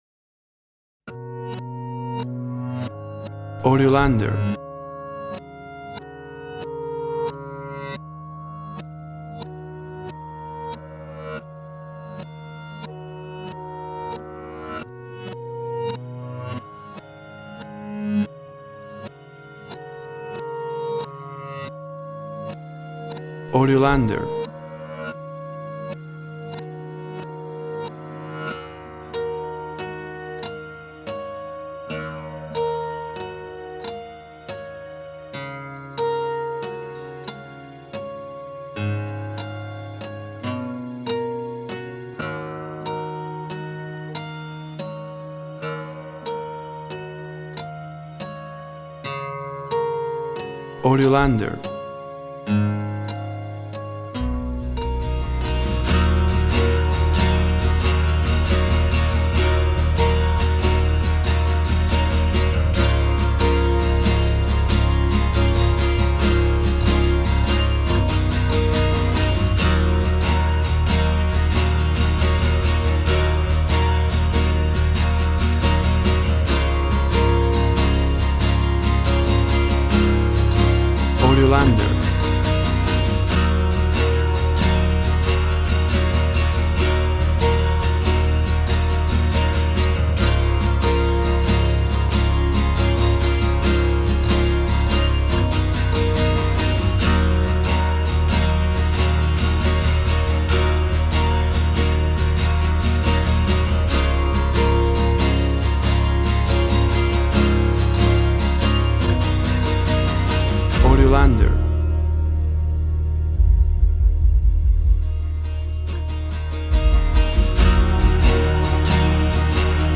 Tempo (BPM) 60/120